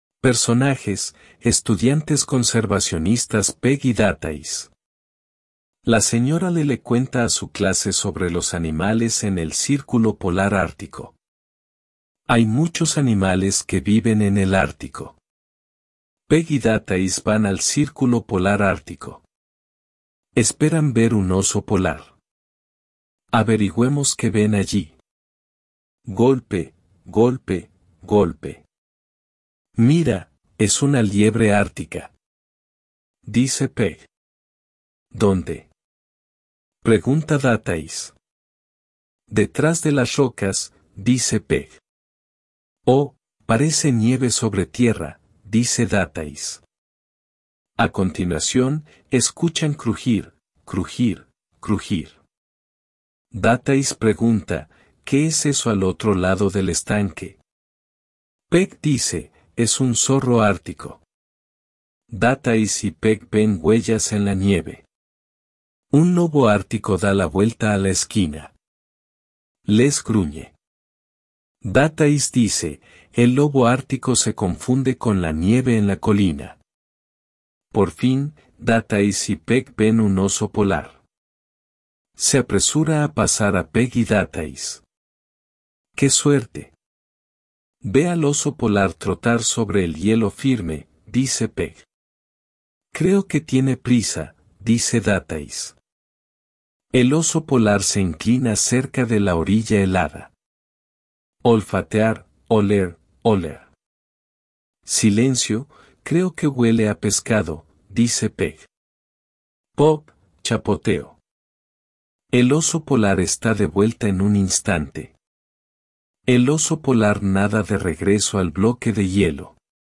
cc_picture_book2 Historias de libros ilustrados 1 ;huìběn gùshì 1